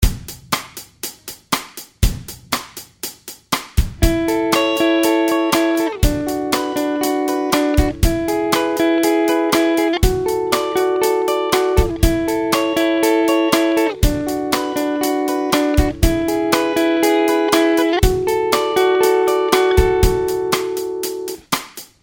These high arpeggios work well when played with another guitar strumming chords.
Arpeggios Using CAGED Chords | Download